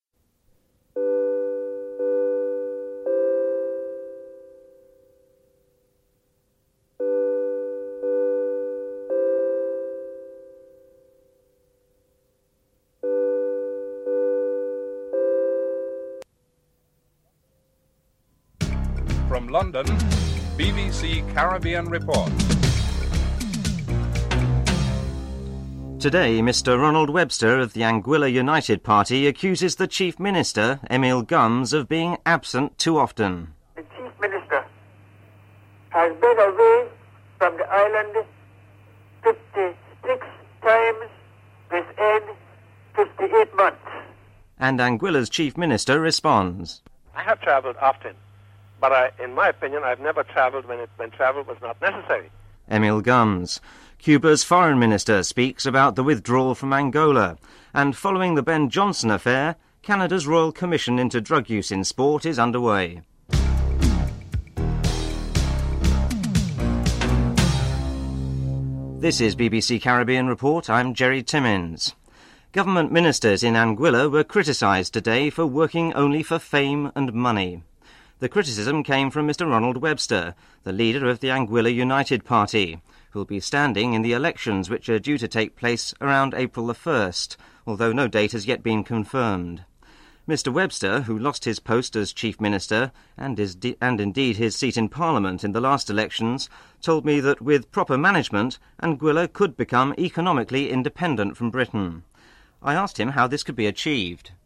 Interviews with Ronald Webster, Leader of the Anguilla United Party and Chief Minister of Anguilla, Emile Gumbs (01:12-08:06)
Interview with Cuba's Deputy Foreign Minister, Ricardo Alarcon who played a key role in the agreement for the troops' withdrawal (08:07-10:42)